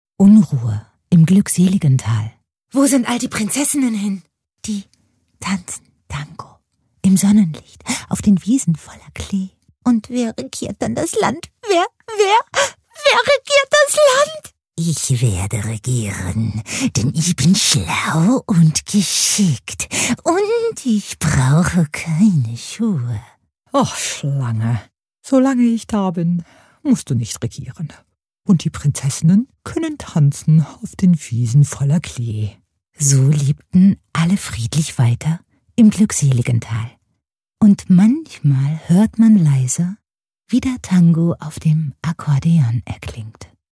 Female
Approachable, Bright, Character, Confident, Conversational, Corporate, Engaging, Friendly, Natural, Reassuring, Smooth, Versatile, Warm
DE-Commercial-car.mp3
Microphone: Rode NT1
Audio equipment: Focusrite Scarlett audio interface, pop filter, soundproof cabin